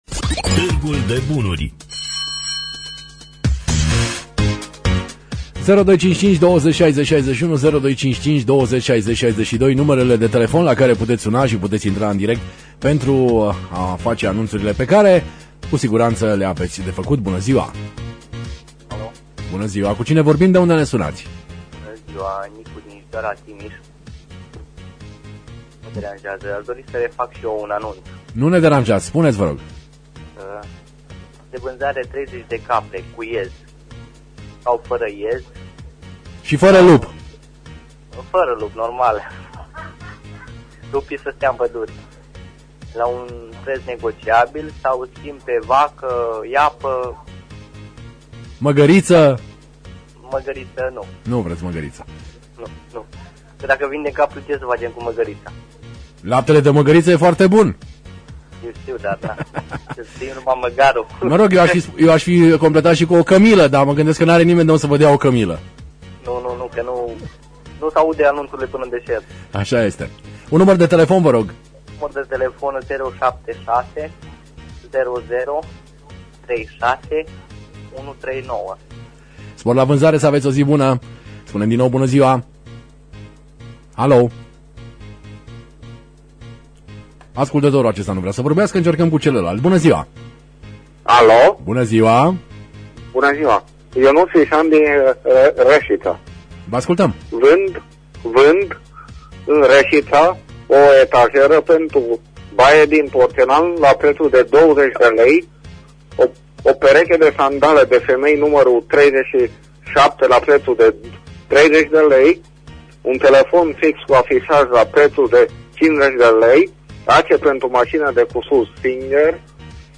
Înregistrarea emisiunii „Târgul de bunuri” de miercuri, 09.03.2016, difuzată la Radio România Reşiţa.